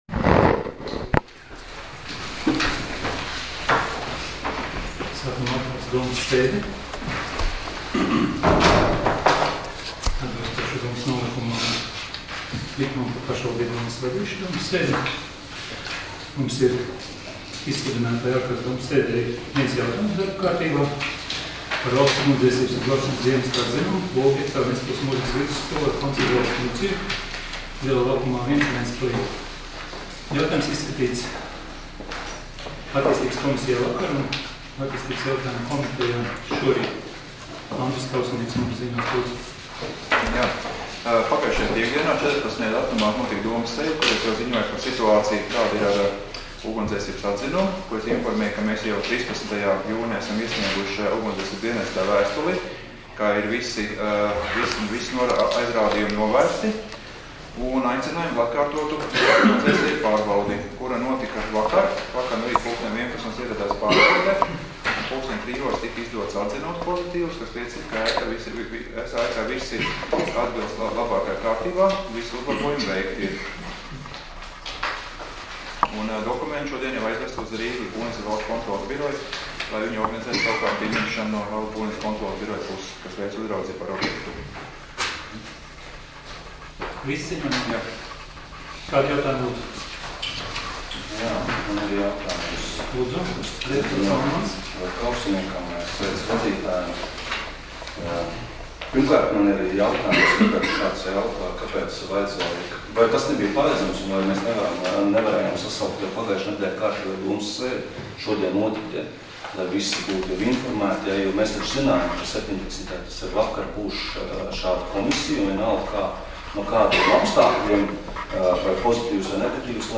Ārkārtas domes sēdes 18.06.2019. audioieraksts